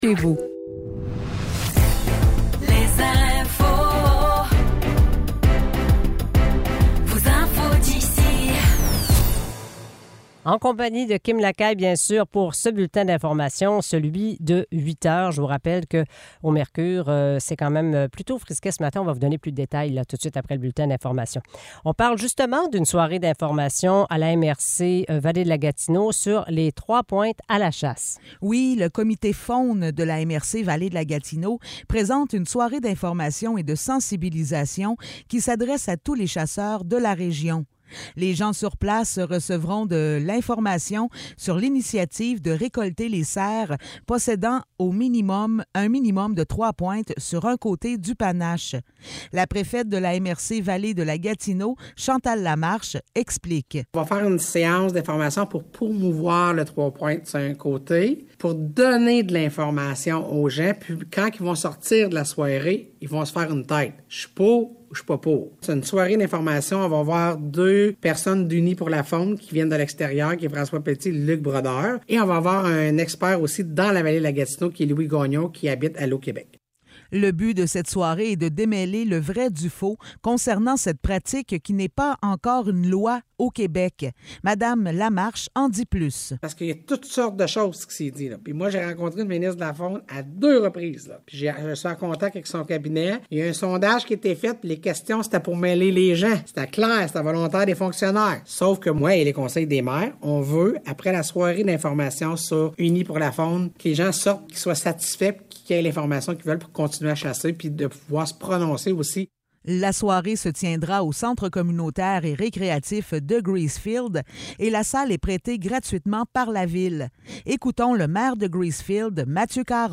Nouvelles locales - 23 octobre 2023 - 8 h